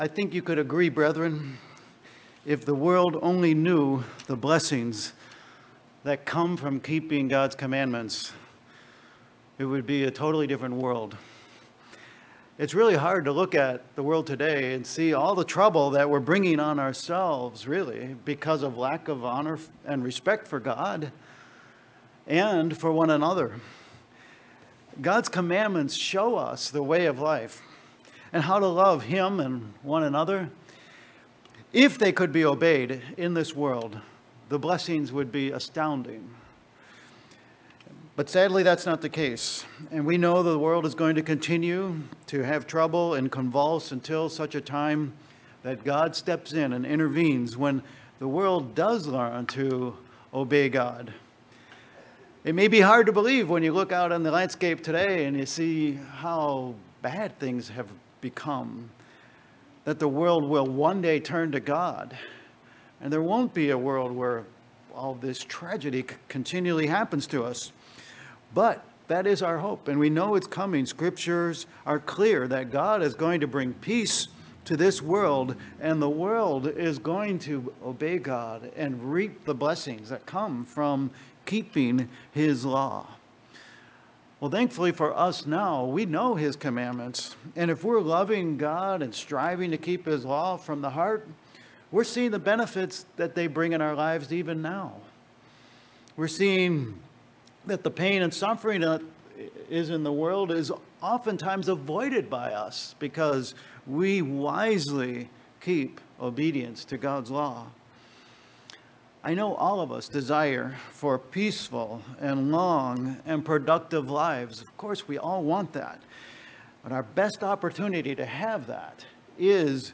Given in Worcester, MA